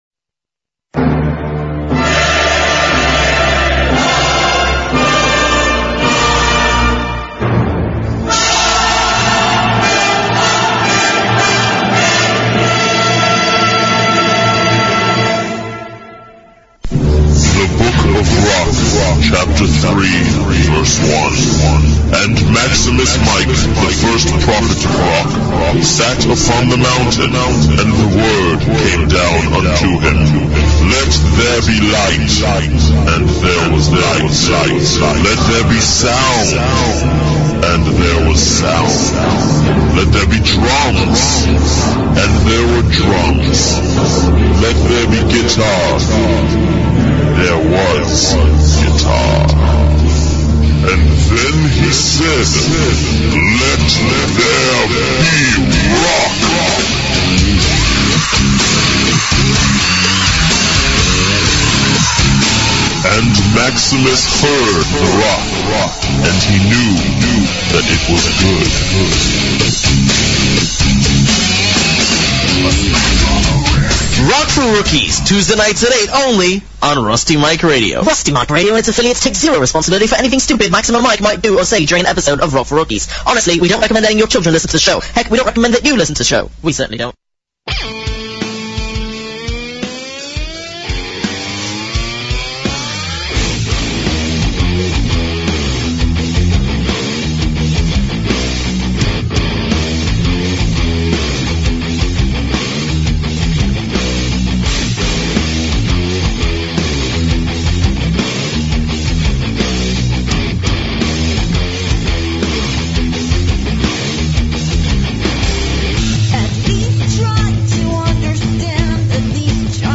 Israels awesome Alt Metal band gets Laid and talks about their new album and big show along with amazing rock music!! We apologize for the poor quality of this week’s show due to faulty recording.